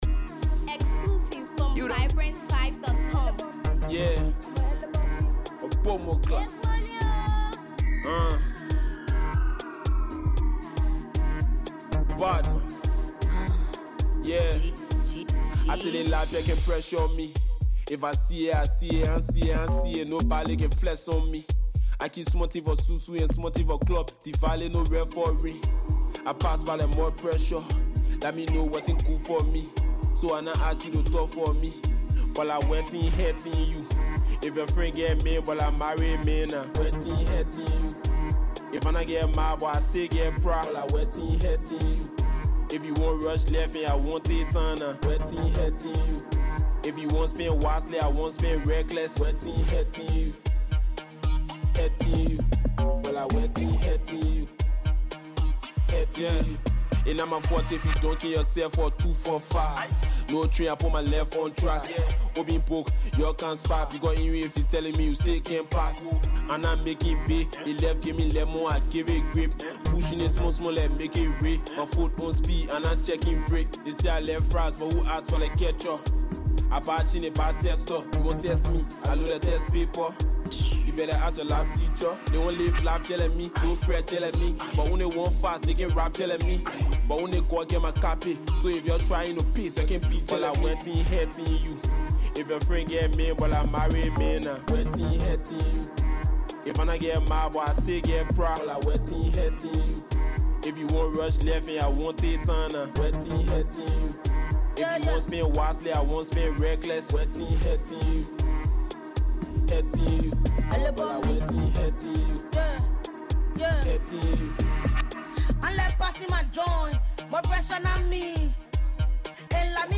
from track 1 to track 4 is a hard tempo!